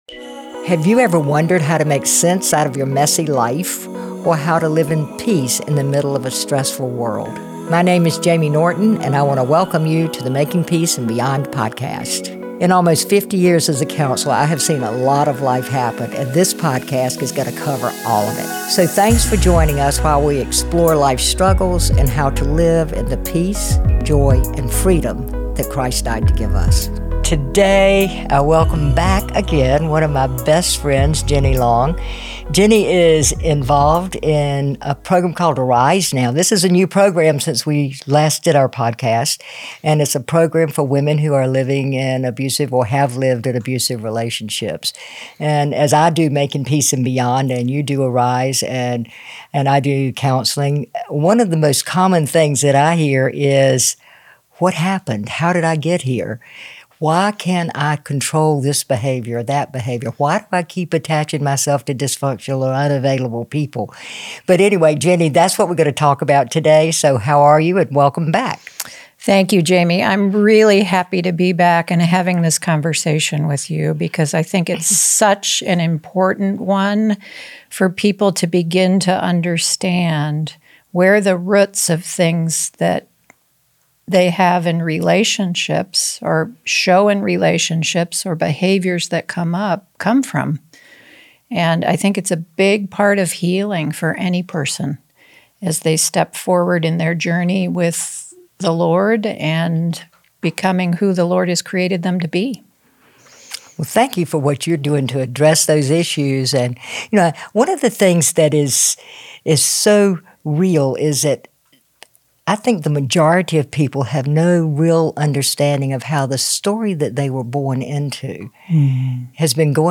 Whether you’ve struggled with codependency, trauma, abuse recovery, or identity in Christ , this conversation will encourage you that you’re not alone.